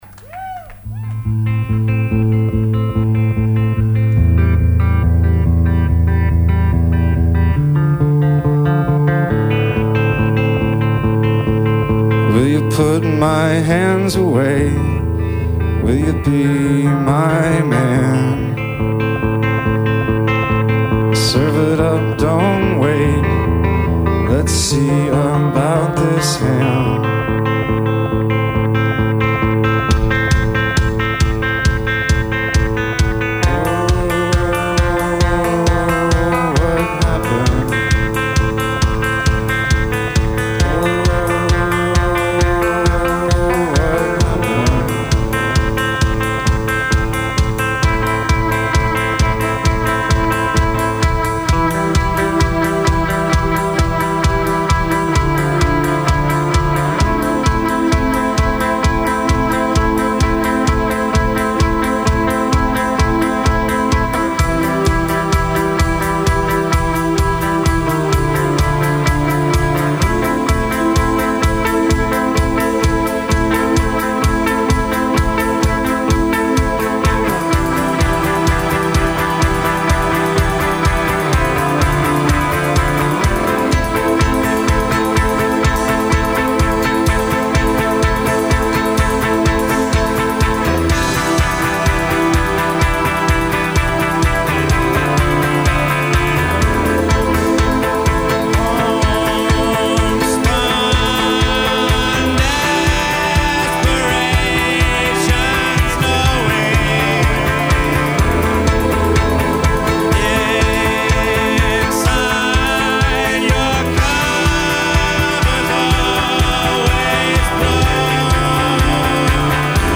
enregistrée le 27/08/2002  au Studio 105